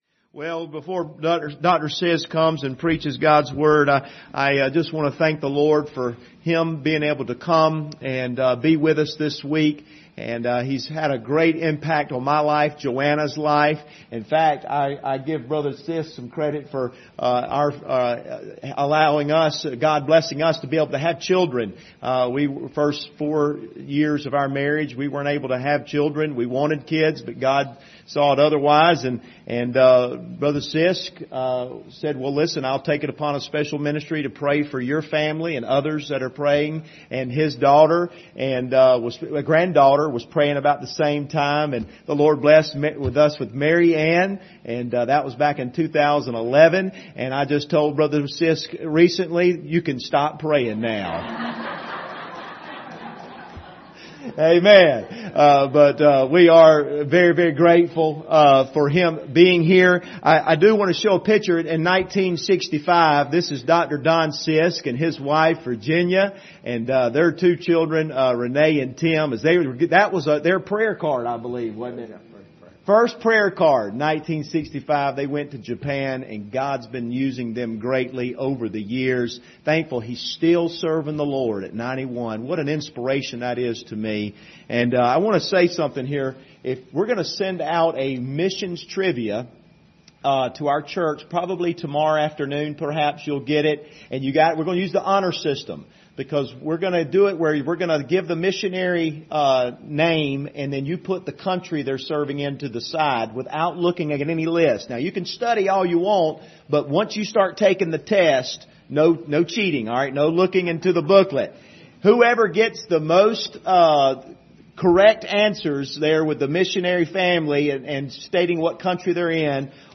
2 Corinthians 8:8-10 Service Type: Sunday Evening Topics